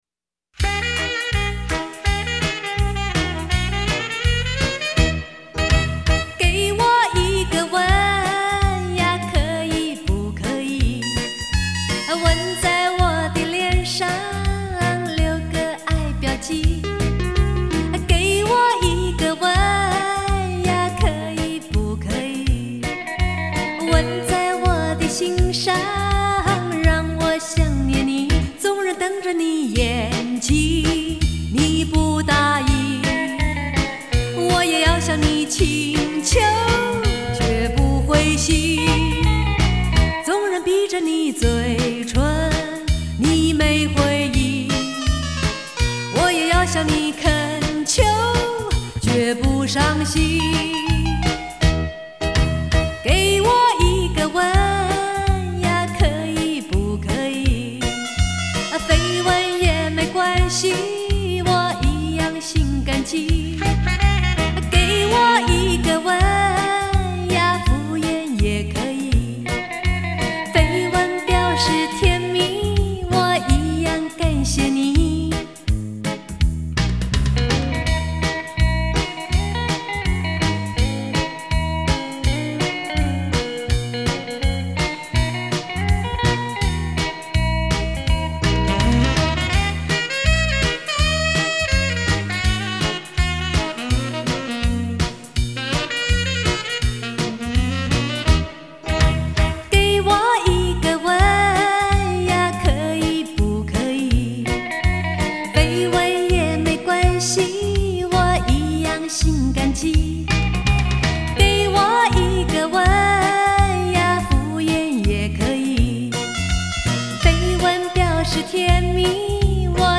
低品质